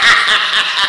Hahahha Sound Effect
hahahha.mp3